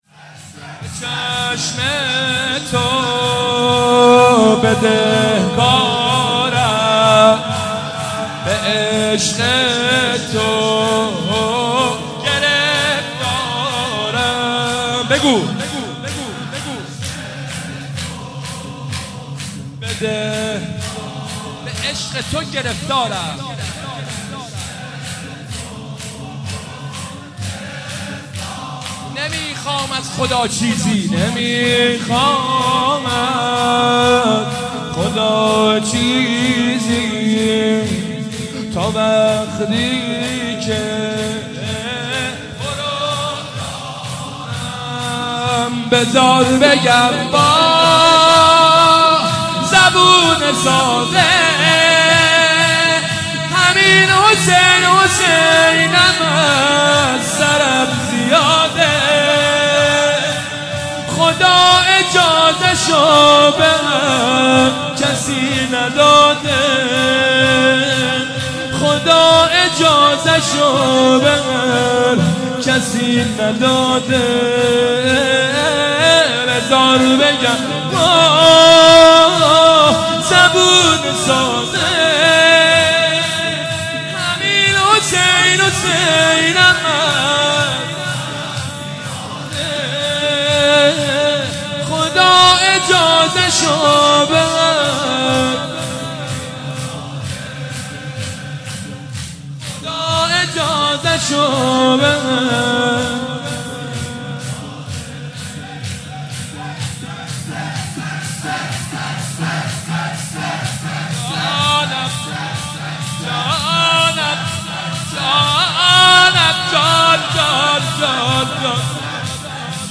مداحی و نوحه
مداحی فاطمیه
(شور)